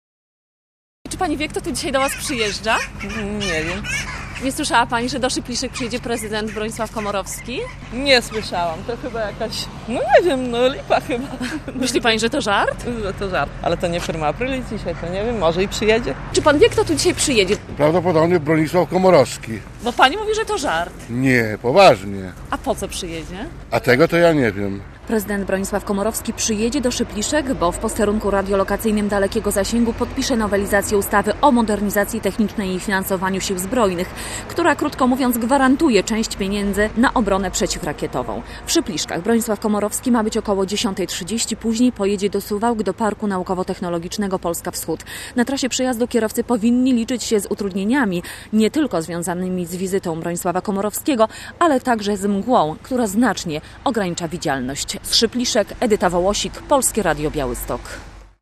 Zapowiedź wizyty prezydenta w Szypliszkach - relacja